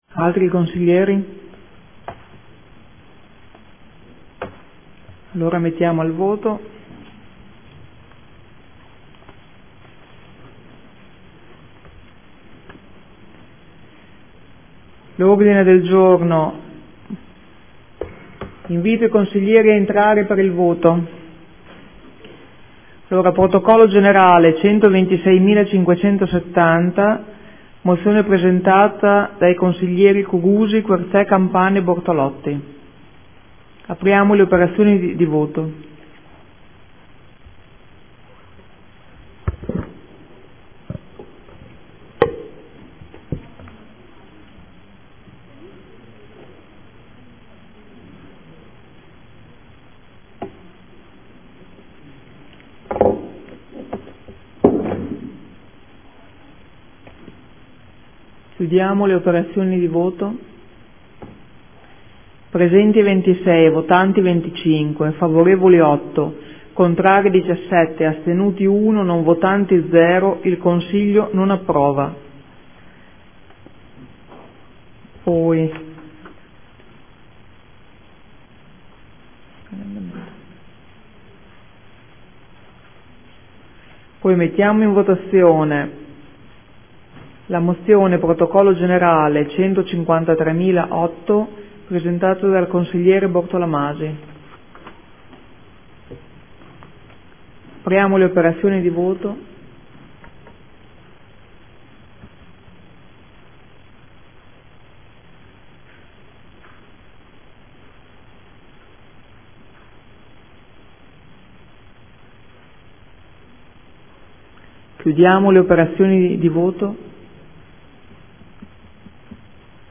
Seduta del 27/11/2014. mettei ai voto Odg. 126570 respinto.